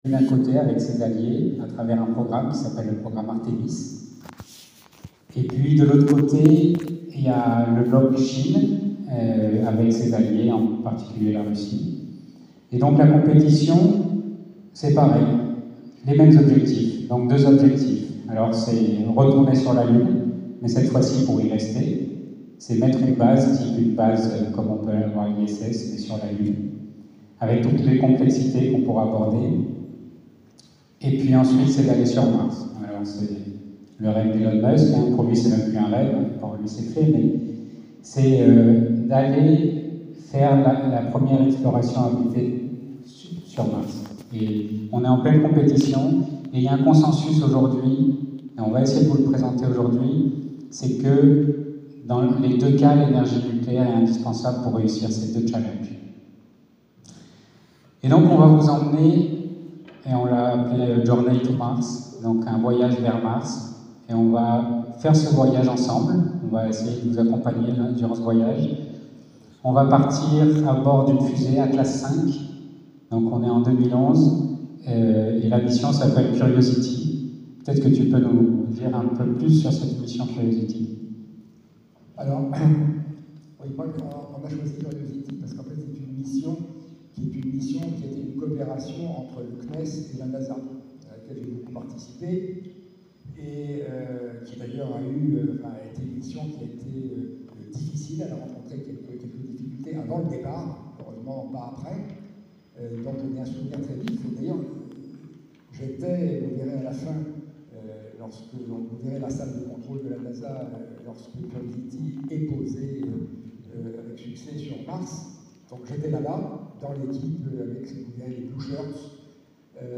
audio conférence